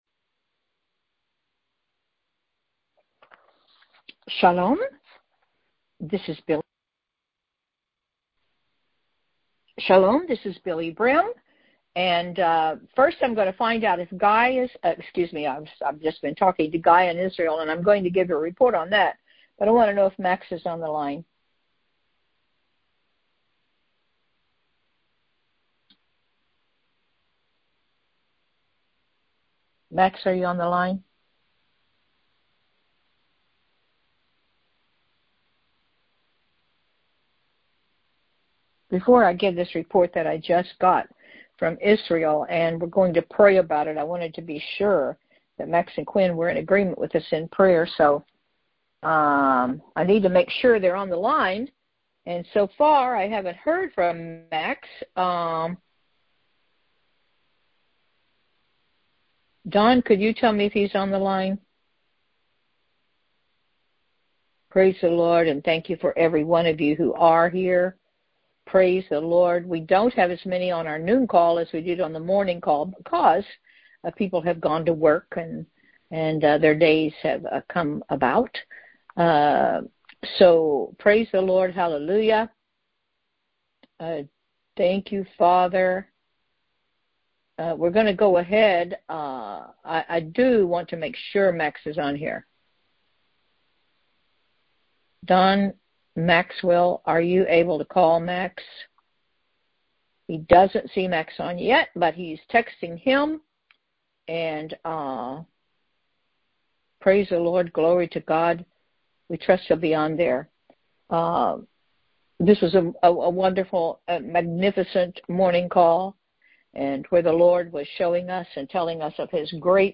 Wednesday Noon Prayer
The audio was recorded via our BBM Phone Cast system.